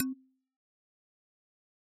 sfx_notify_tip.mp3